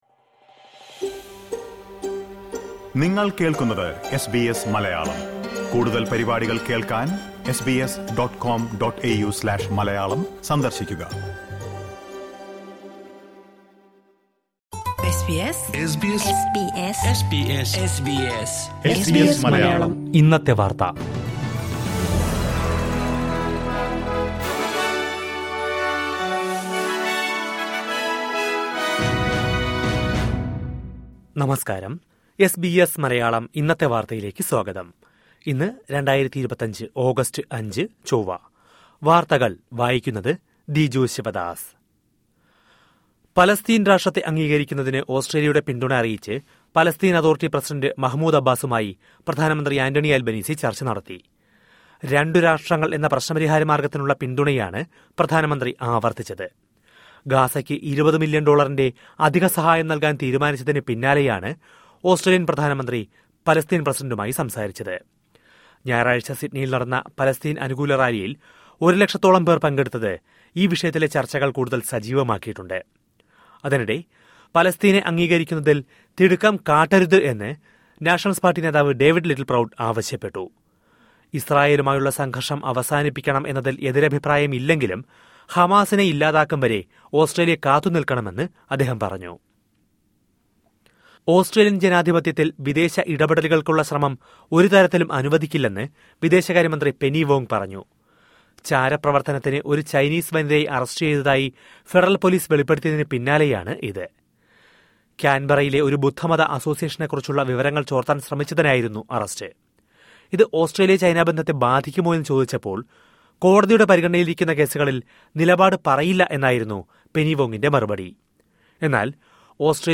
2025 ഓഗസ്റ്റ് അഞ്ചിലെ ഓസ്ട്രേലിയയിലെ ഏറ്റവും പ്രധാന വാർത്തകൾ കേൾക്കാം...